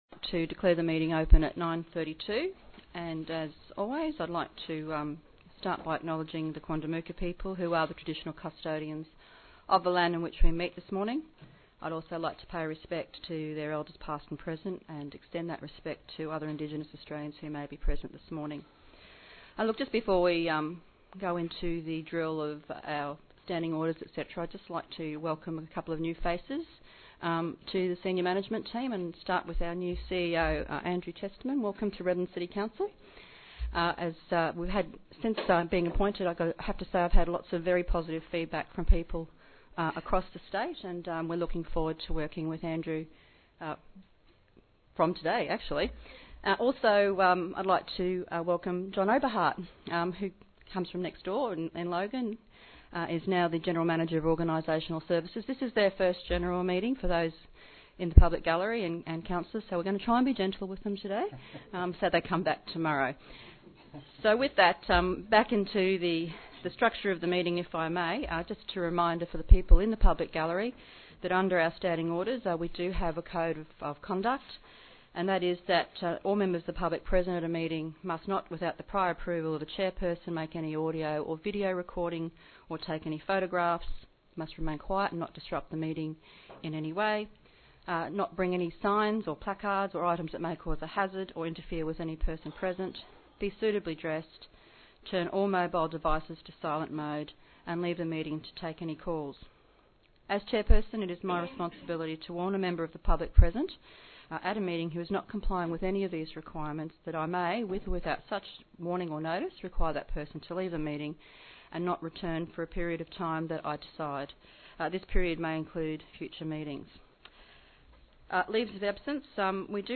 19 April 2017 General meeting Audio recording